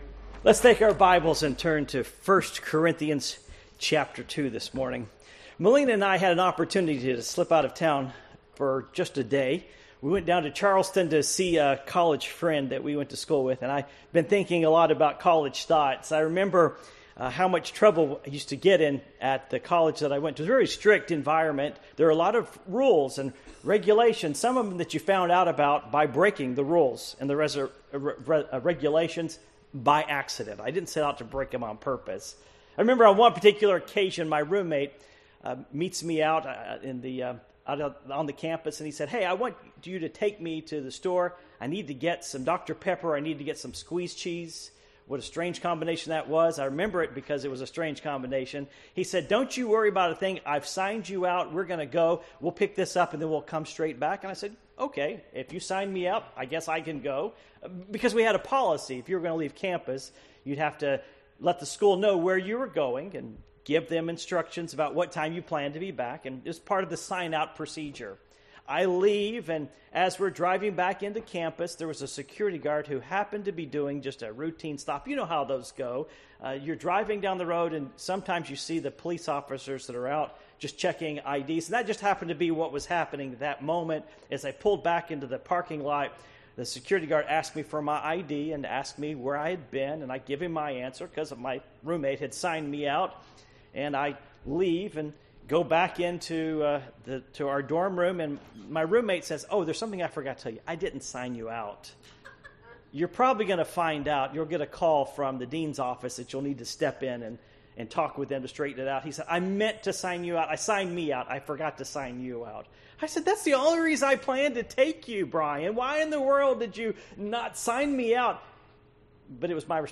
Passage: 1 Corinthians 2:6-11 Service Type: Morning Worship